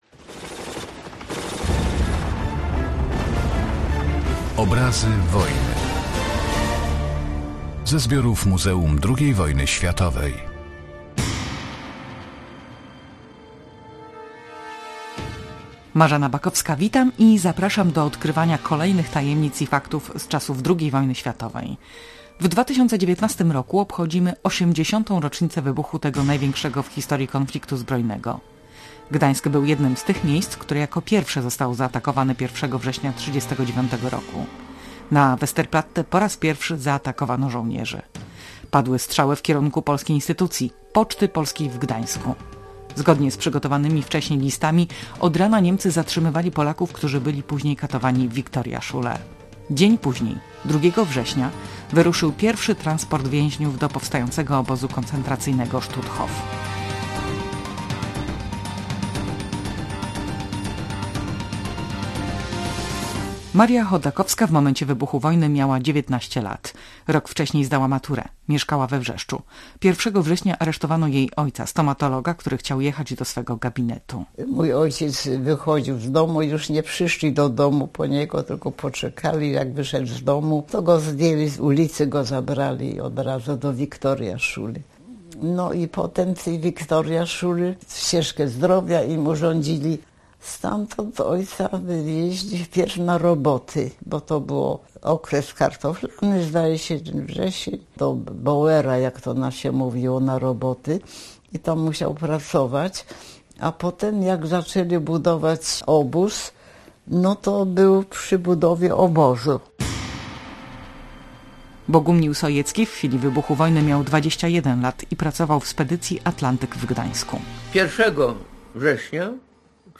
W roku, w którym obchodzimy 80. rocznicę wybuchu II wojny światowej, przypominamy w audycji jak rozpoczął się ten największy konflikt zbrojny na świecie. Oddajemy głos świadkom
Zabierają też głos eksperci: